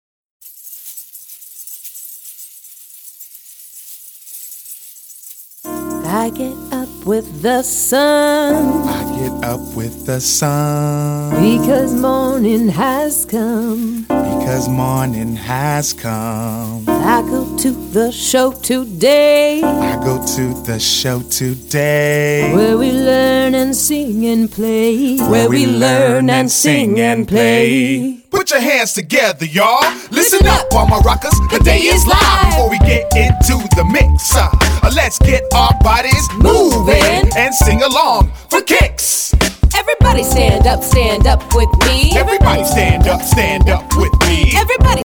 Rap and sing about counting, friendship, shapes and coins